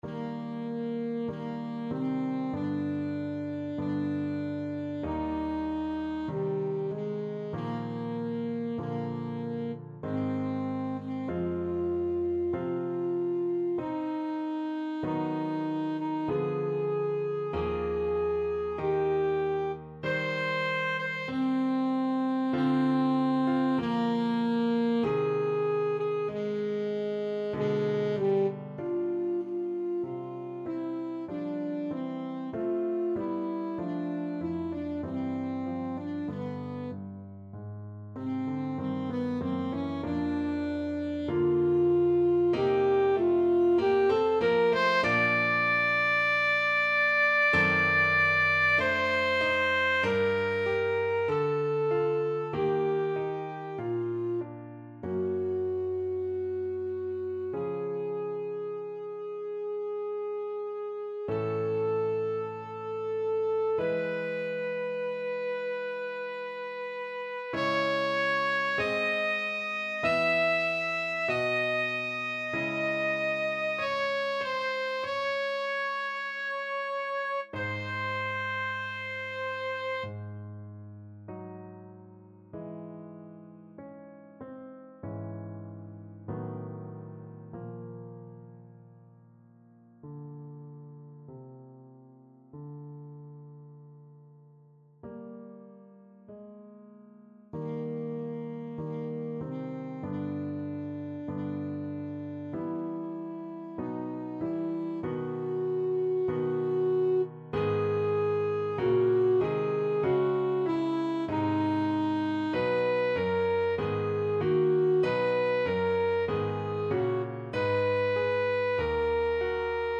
Classical Bruckner, Anton Ave Maria, WAB 7 Alto Saxophone version
Eb major (Sounding Pitch) C major (Alto Saxophone in Eb) (View more Eb major Music for Saxophone )
~ = 96 Alla breve. Weihevoll.
2/2 (View more 2/2 Music)
Classical (View more Classical Saxophone Music)